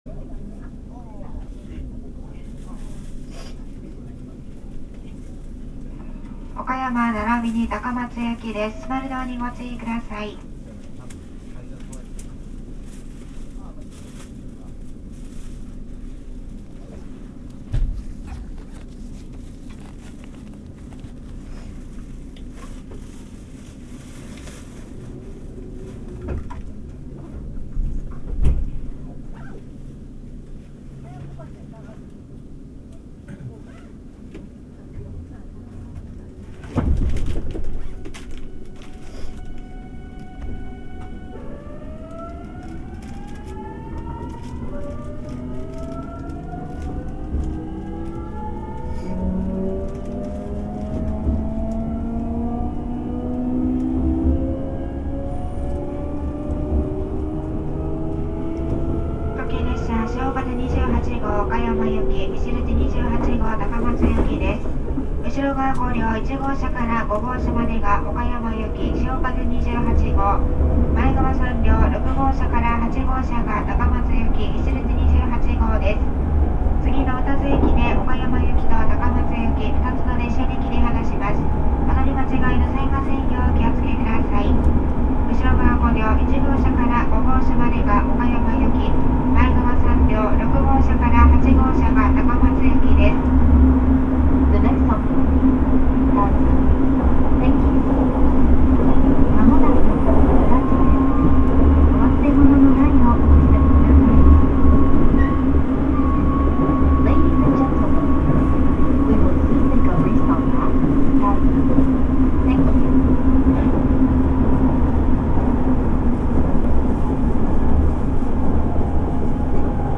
走行機器はGTO素子によるVVVFインバータ制御で、定格200kWの三相誘導電動機を制御します。
走行音
録音区間：丸亀～宇多津(しおかぜ28号・いしづち28号)(お持ち帰り)